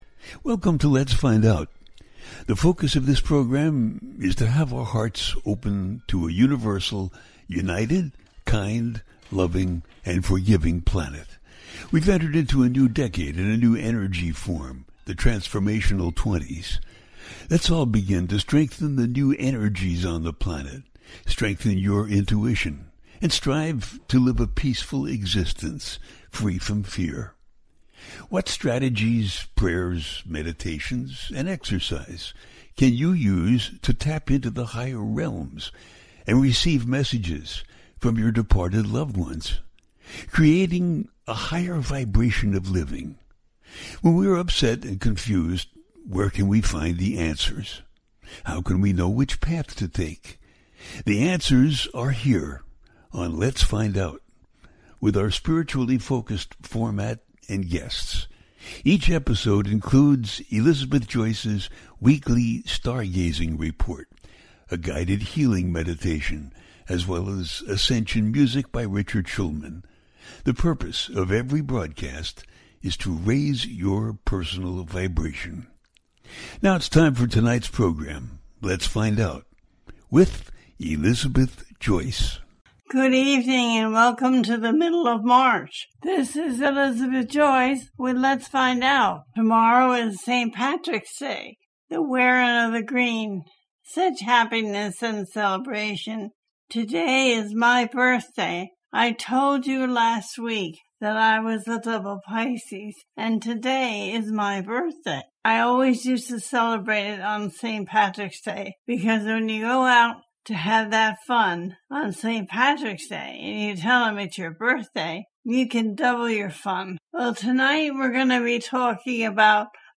Transforming Our Lives In March 2025 - A teaching show
The listener can call in to ask a question on the air.
Each show ends with a guided meditation.